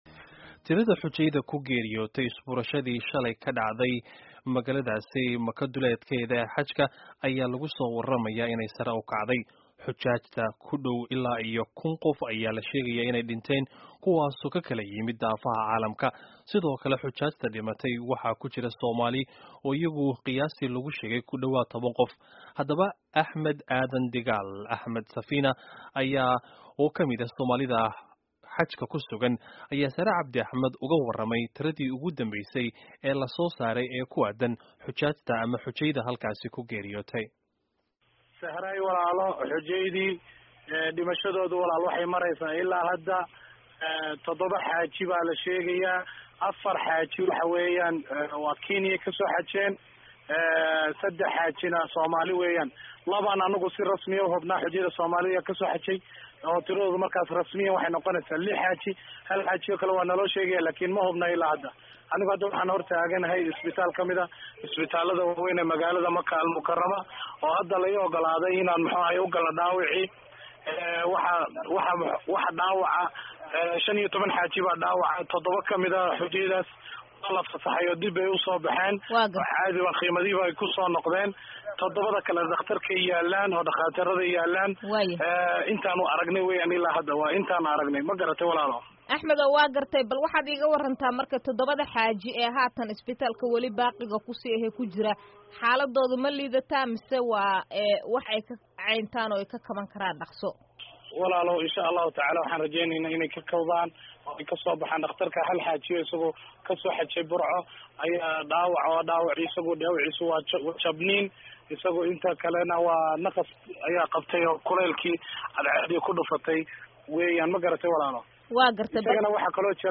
Wareysiga Tirada Dhimashada Ee Xajka